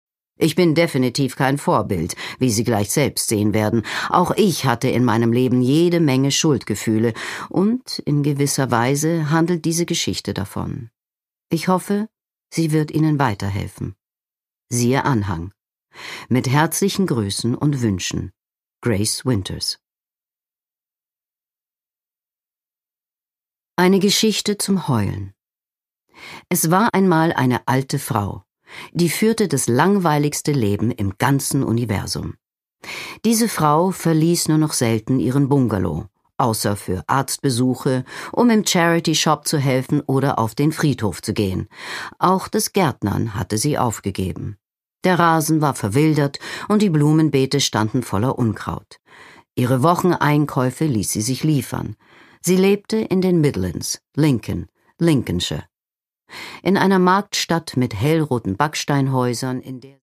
Produkttyp: Hörbuch-Download
Gelesen von: Leslie Malton